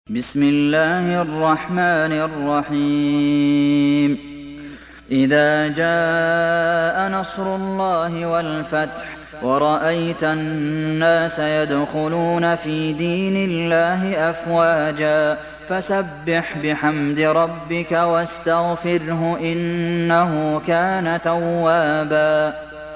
دانلود سوره النصر mp3 عبد المحسن القاسم روایت حفص از عاصم, قرآن را دانلود کنید و گوش کن mp3 ، لینک مستقیم کامل